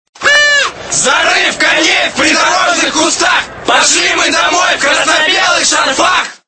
Список файлов рубрики Футбольные кричалки